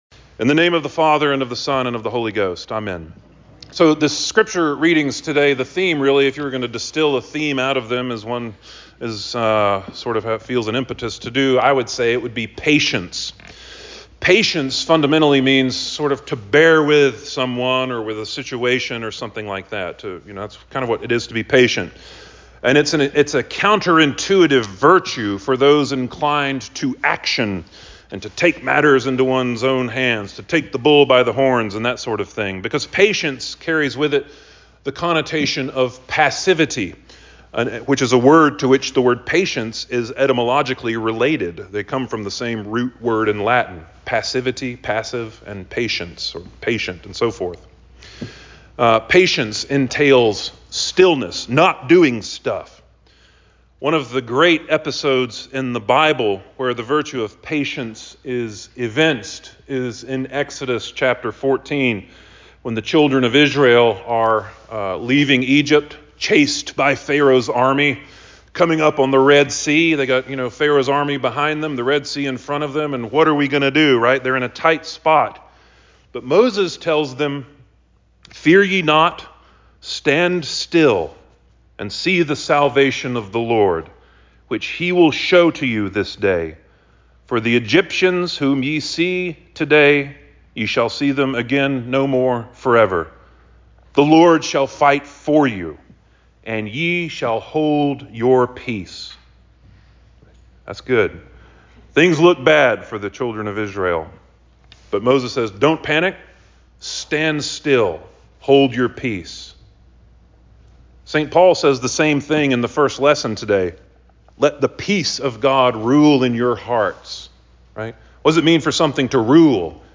Sermon for the Fifth Sunday After Epiphany 2.9.2025